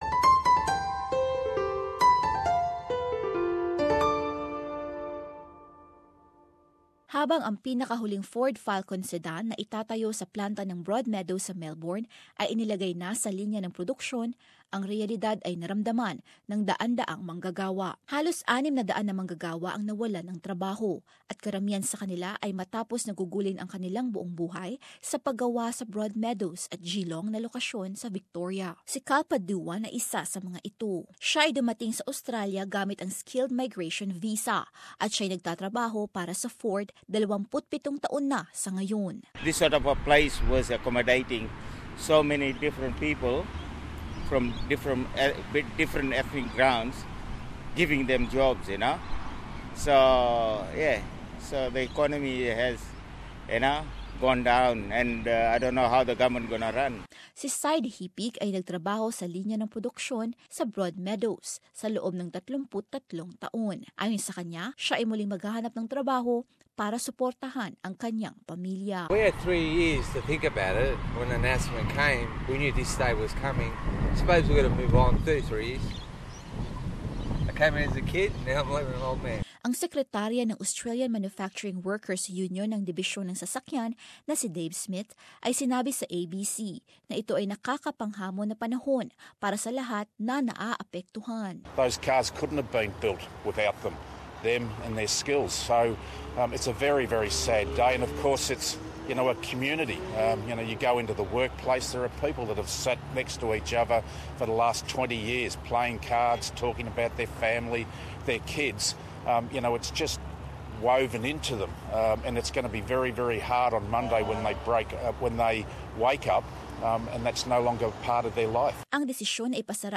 In this report, there are concerns for hundreds of workers who now face an uncertain future.